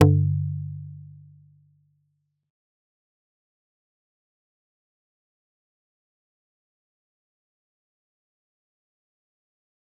G_Kalimba-G2-mf.wav